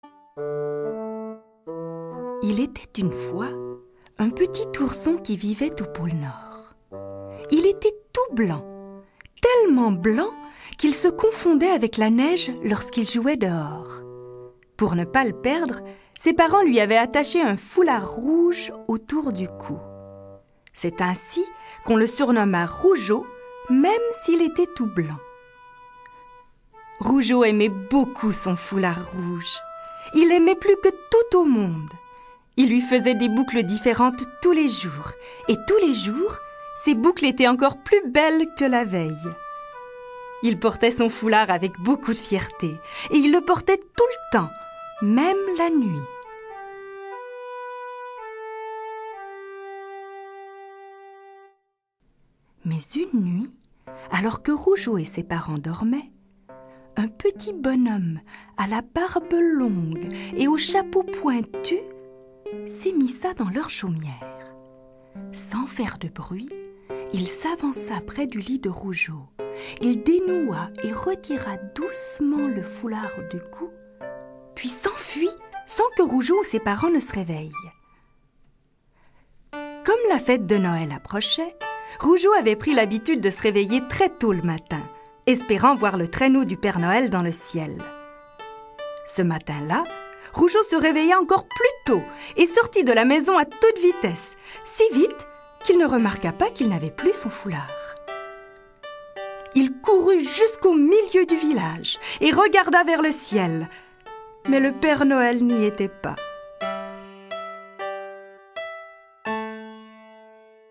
2015 Trois merveilleux contes étincelants comme des étoiles de Noël qui, agrémentés de musique du temps des fêtes, ne manqueront pas de séduire tous les membres de la famille.